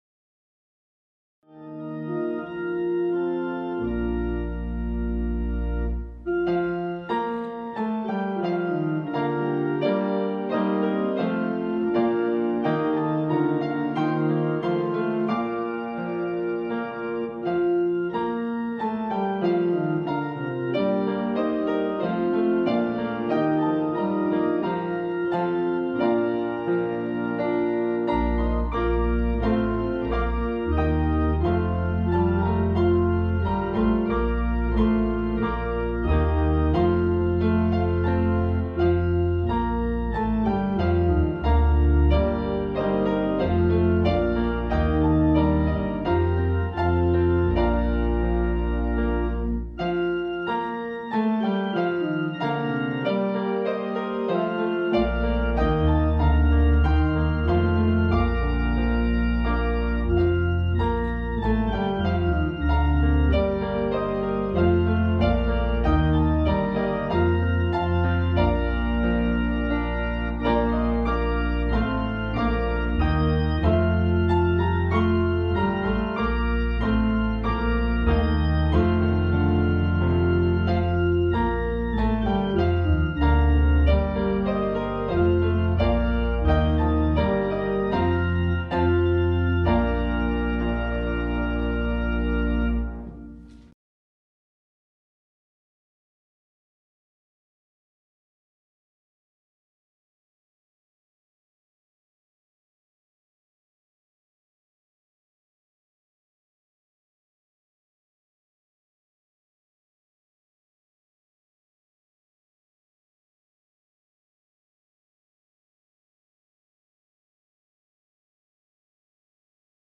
We held virtual worship on Sunday, March 28, 2021 at 10:00am You may watch a recording on Facebook clicking here, or you may watch below.